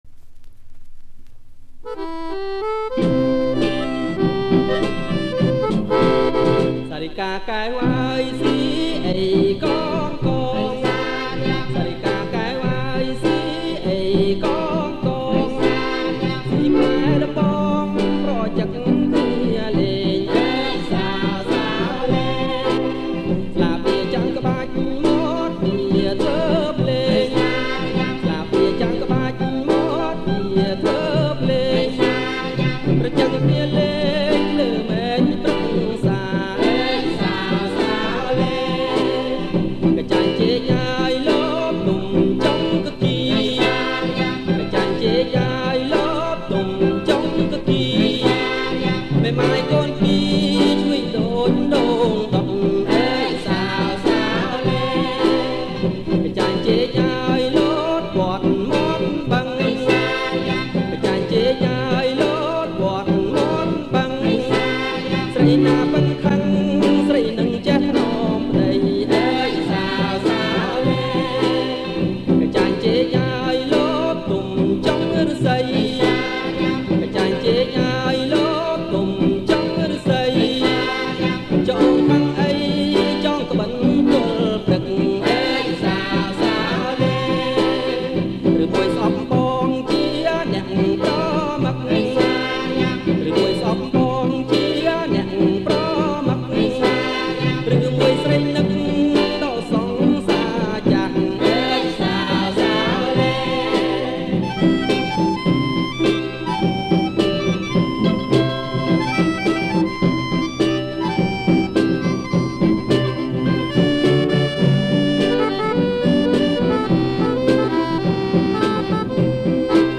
• បទភ្លេងប្រជាប្រិយ
• ចង្វាក់ Saravan